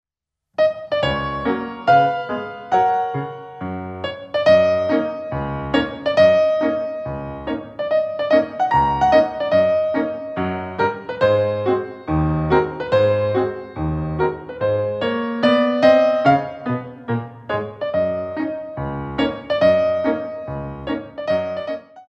Medium Allegro In 2